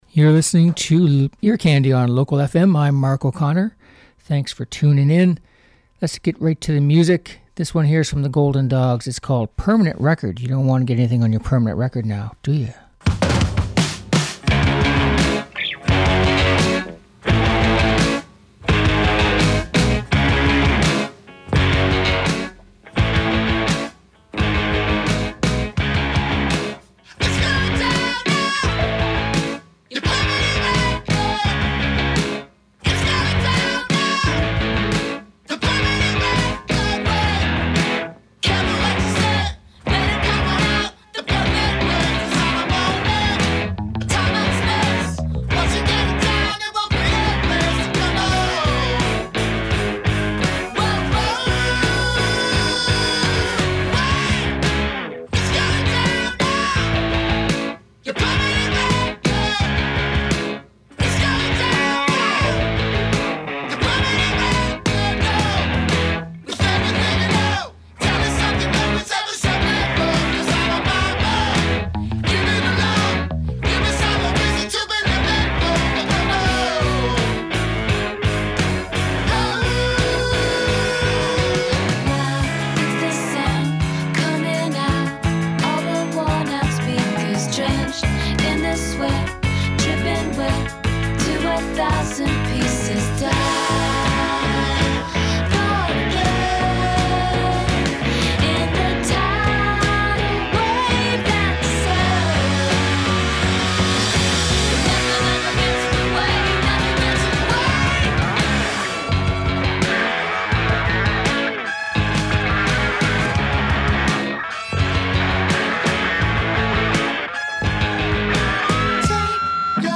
Uptempo Rock and Pop Songs